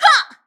m_721_attack_02.wav